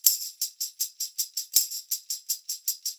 80 TAMB1.wav